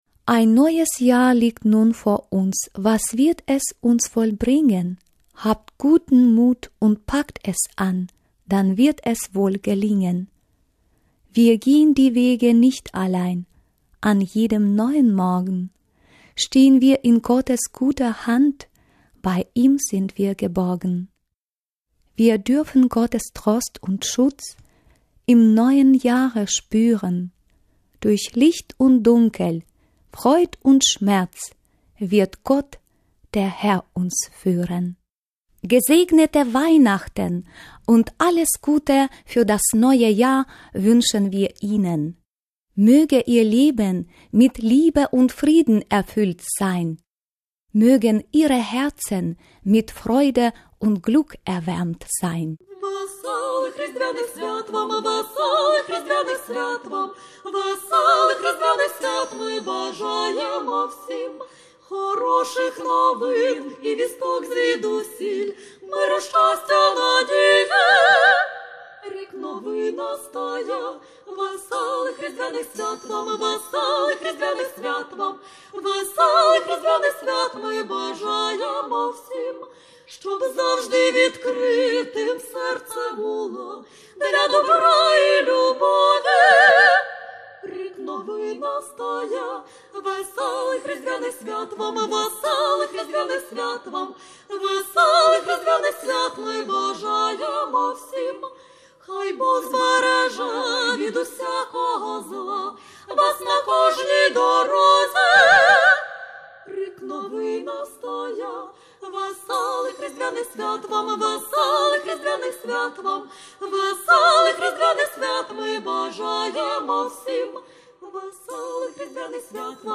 Weihnachtsgrüße aus der Ukraine in Ost-Westfalen-Lippe
Volksmusik Ensembles "Wundersaiten" und "Quellen" von der Lessya-Universität
Die beiden Bandura-Trios "Wundersaiten" erfreuen uns dieses Jahr mit Unterstützung der Instrumental Gruppe "Quellen" mit ukrainischen Volksliedern und Liedern zur Advents- und Weihnachtszeit.
Bandura "Wundersaiten"